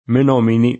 vai all'elenco alfabetico delle voci ingrandisci il carattere 100% rimpicciolisci il carattere stampa invia tramite posta elettronica codividi su Facebook Menominee [ingl. mën 0 mini ] top. (S. U.) ed etn. — come etn., anche italianizz. in menomini [ men 0 mini ]